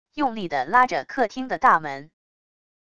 用力的拉着客厅的大门wav音频